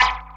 Perc.wav